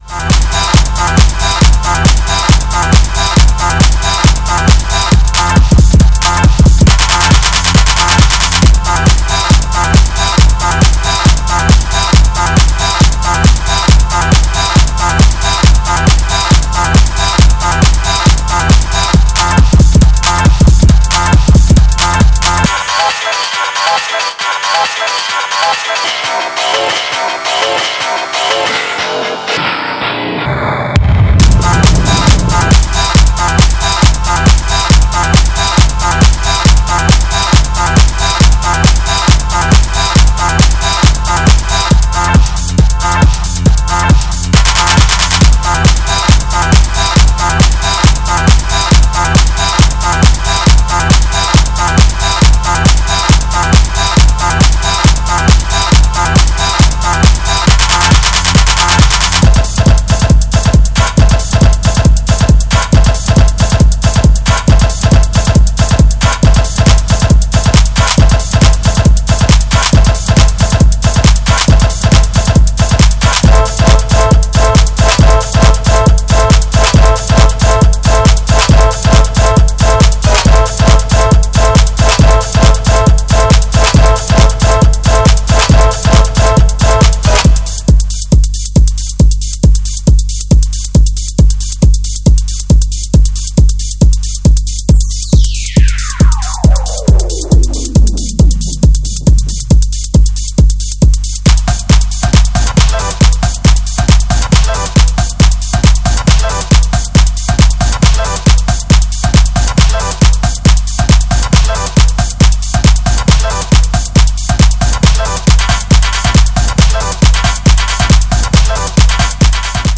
big club tracks
crazy bass music
club bomb
“Crazy disco bizniz” Groove Armada